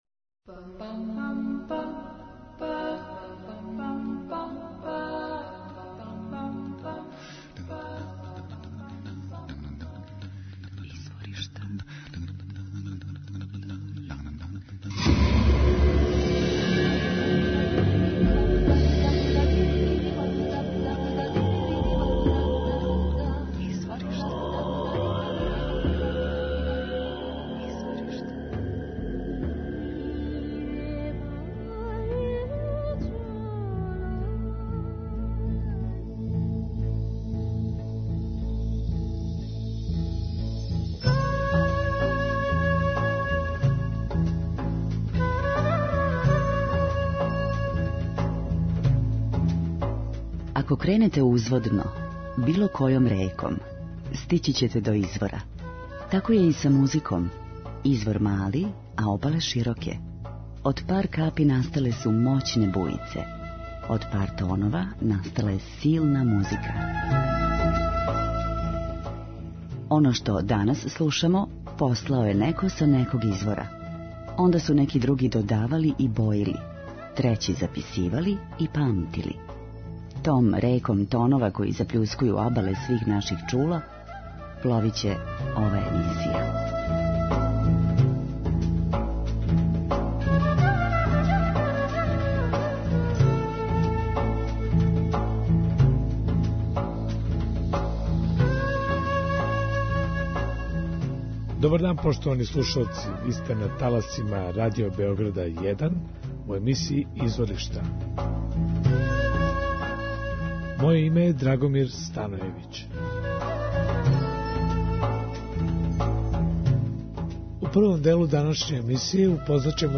драстично меша различите стилове музике